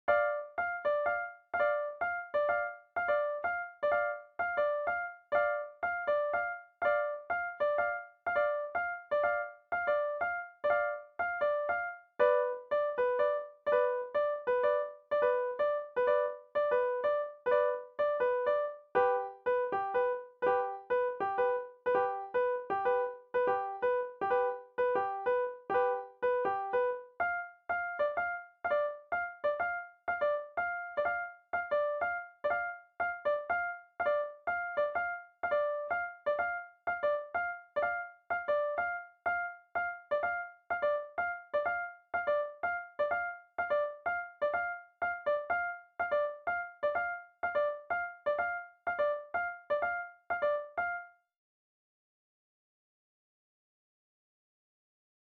The top three staves show similarities in the three patterns.